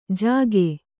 ♪ jāge